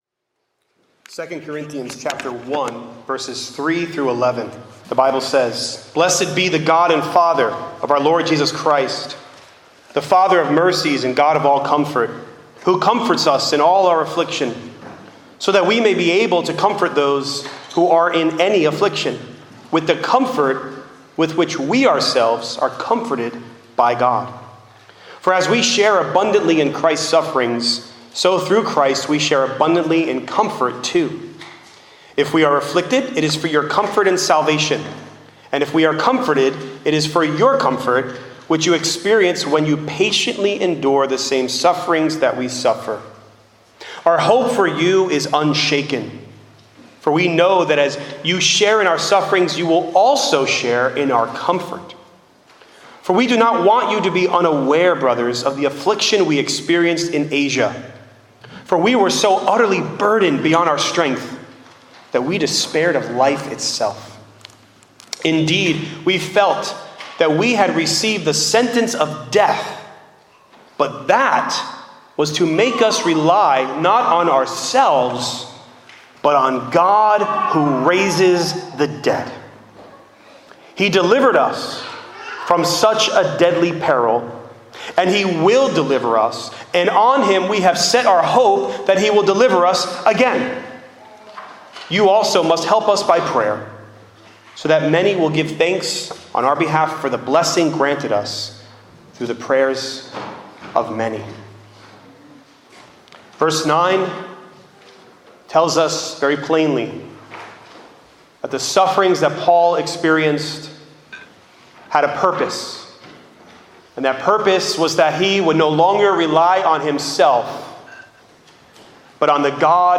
The God Who Raises the Dead | SermonAudio Broadcaster is Live View the Live Stream Share this sermon Disabled by adblocker Copy URL Copied!